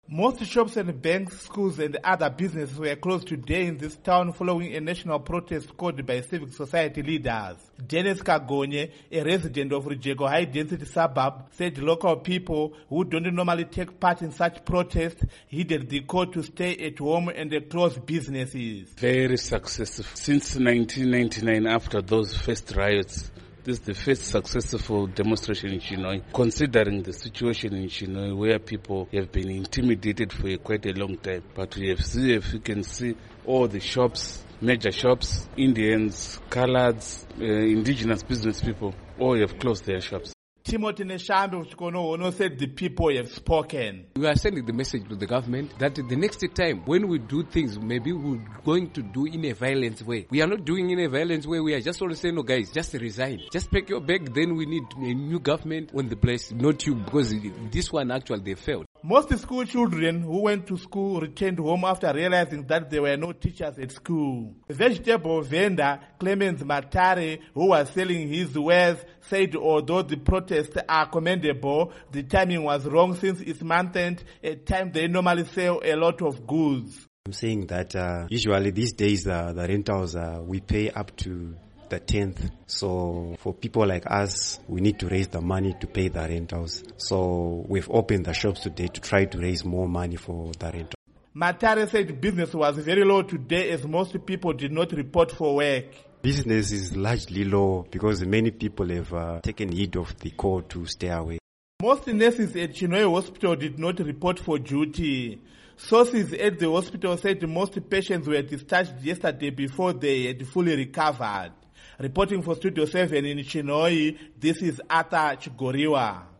Report on Chinhoyi Protests